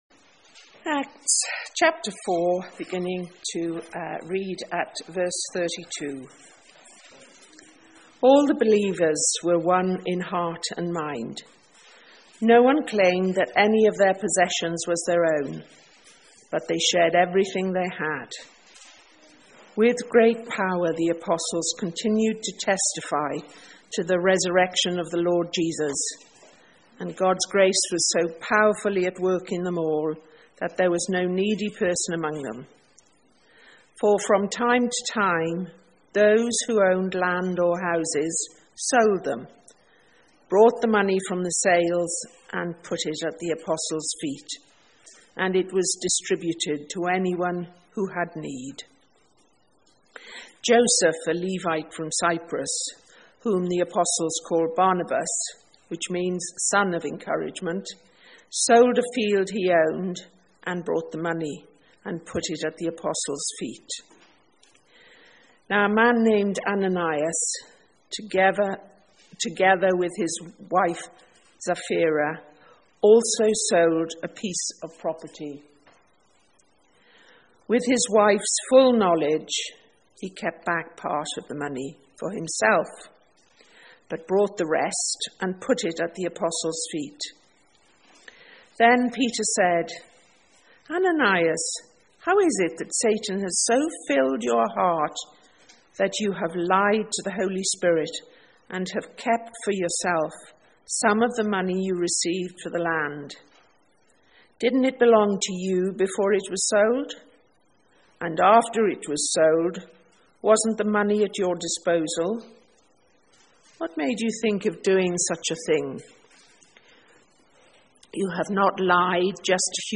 Bible Reading Sermon (Part 1)